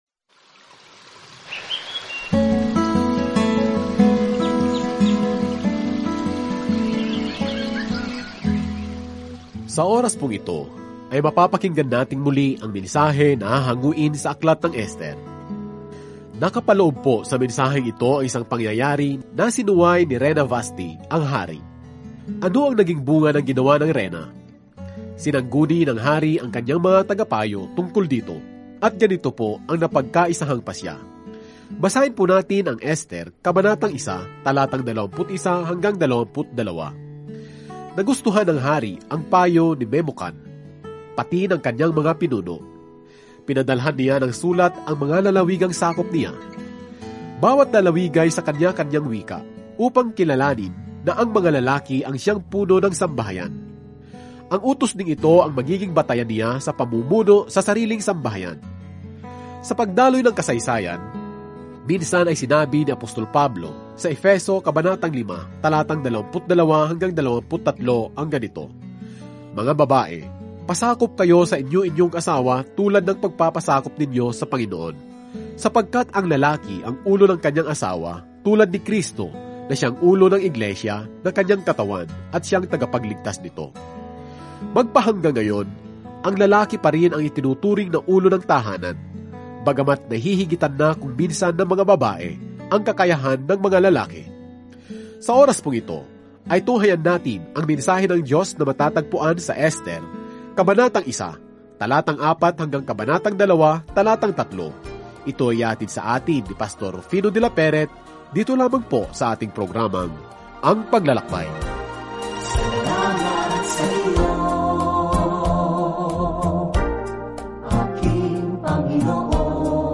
Banal na Kasulatan Ester 1:4-22 Ester 2:1-3 Araw 1 Umpisahan ang Gabay na Ito Araw 3 Tungkol sa Gabay na ito Laging pinangangalagaan ng Diyos ang kanyang mga tao, kahit na ang mga plano ng genocidal ay nagbabanta sa kanilang pagkalipol; isang kamangha-manghang kwento kung paano hinarap ng isang batang babaeng Hudyo ang pinakamakapangyarihang taong nabubuhay upang humingi ng tulong. Araw-araw na paglalakbay kay Esther habang nakikinig ka sa audio study at nagbabasa ng mga piling talata mula sa salita ng Diyos.